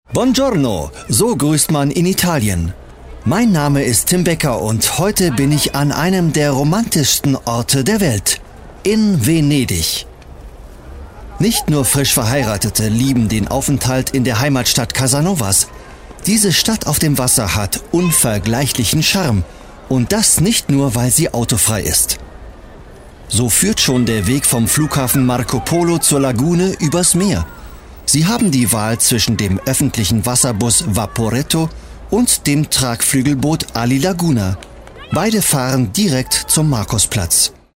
deutscher Profi Sprecher für Werbung, Kommentar, Imagefilme, Podcast, Multimedia, Lernsoftware, E-learning, Spiele uva.
Sprechprobe: eLearning (Muttersprache):